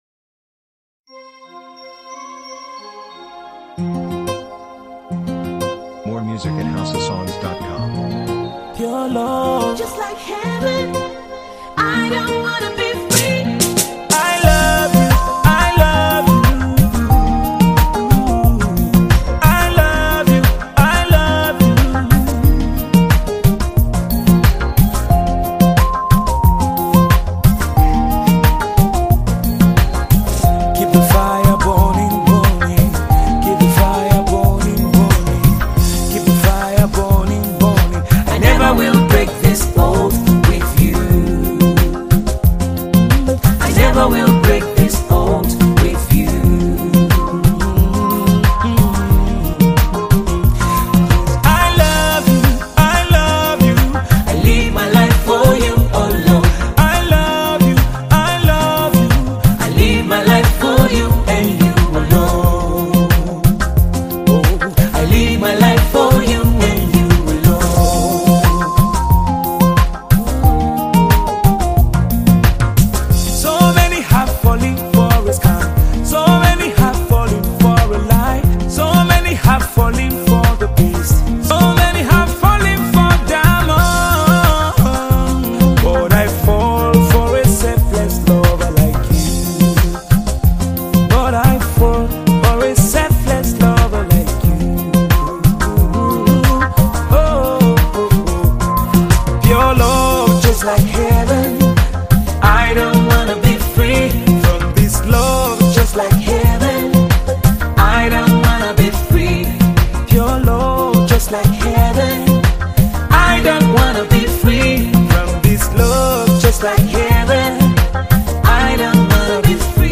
Tiv songs
encouraging, uplifts the spirit and soul